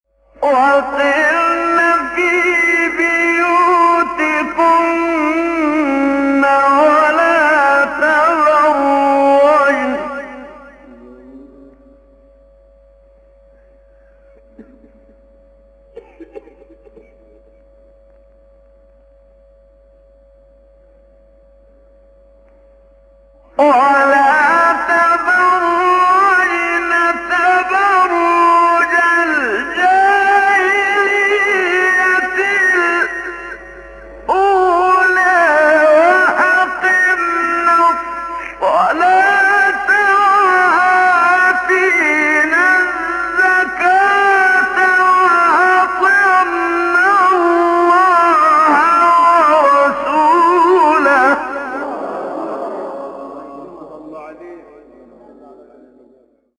تلاوت آیه 33 سوره احزاب استاد منشاوی مقام سه گاه | نغمات قرآن | دانلود تلاوت قرآن